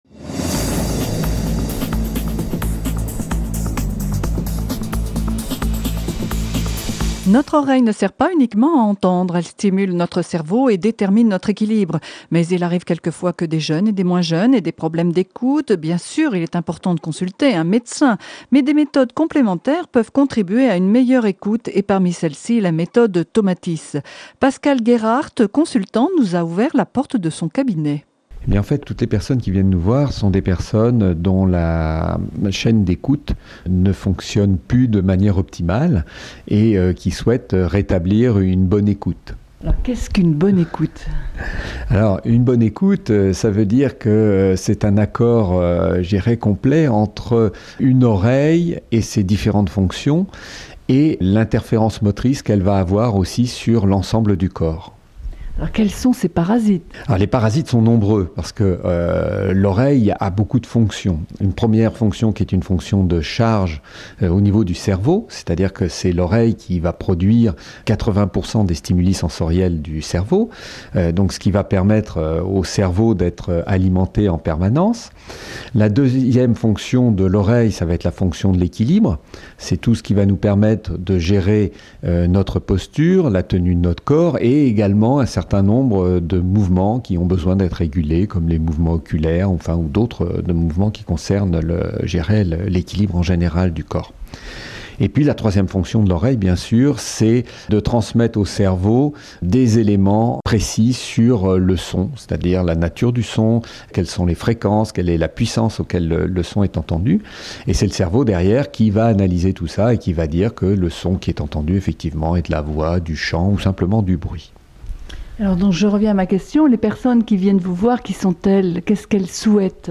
Débat / table ronde